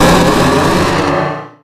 Audio / SE / Cries / BEEDRILL_1.ogg